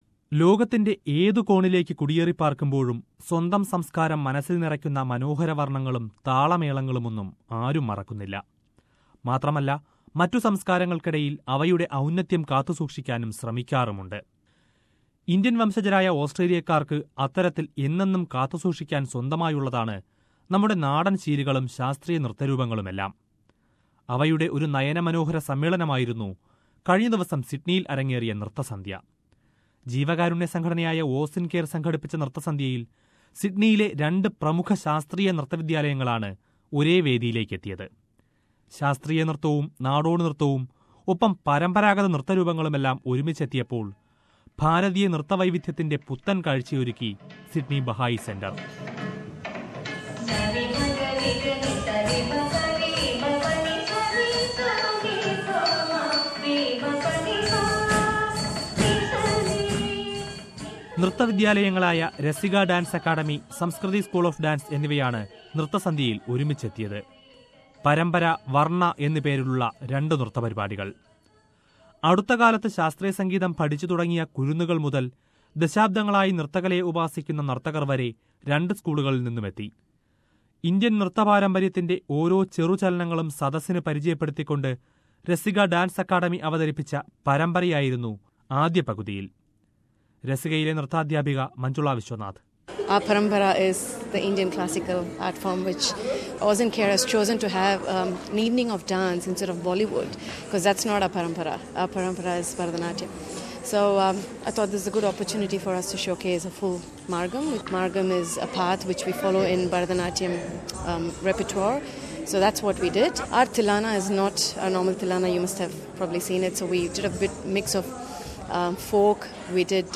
Listen to the coverage of Nritha Sandhya staged by two famous dance schools in Sydney ….